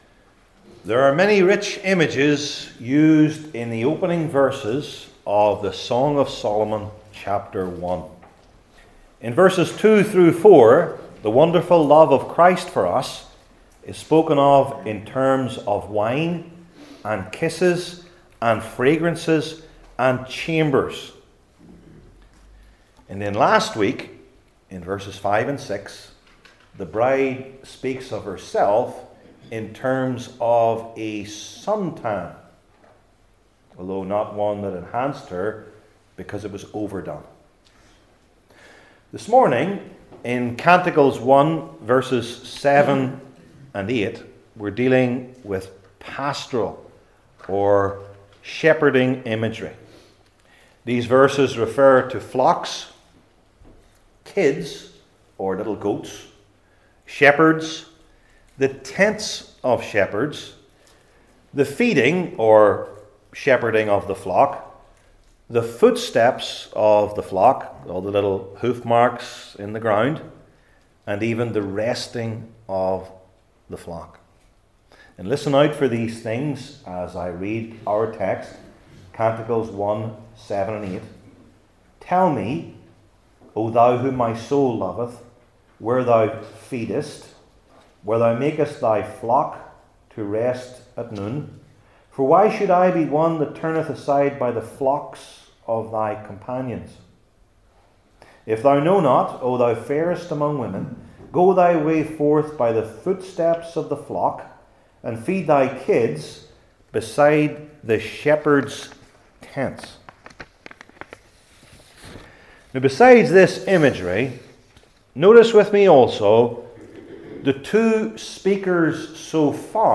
Song of Solomon 1:7-8 Service Type: Old Testament Sermon Series I. The Believer’s Excellent Question II.